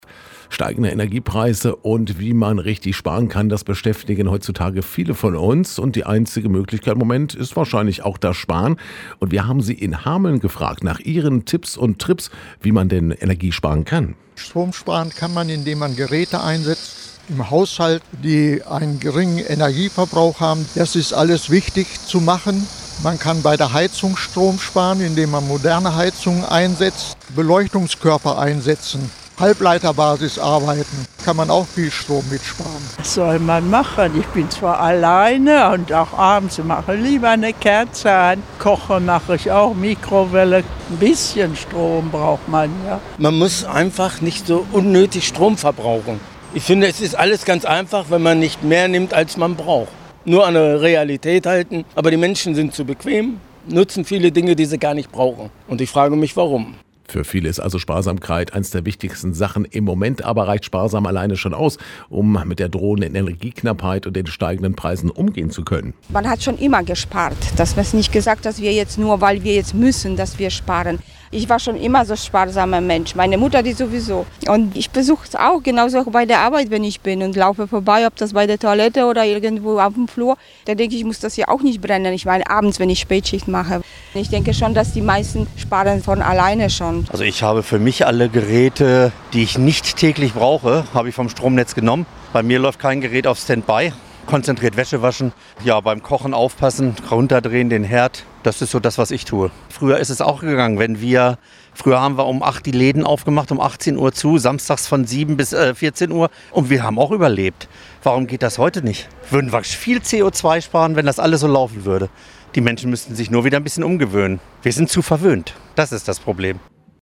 Landkreis Hameln-Pyrmont: UMFRAGE STROMSPAREN